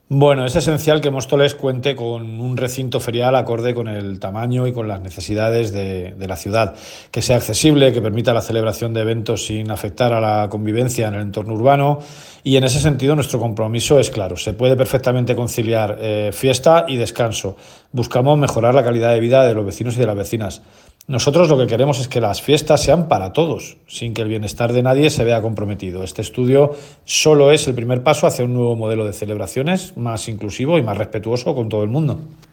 declaraciones-emilio-delgado-recinto-ferial.mp3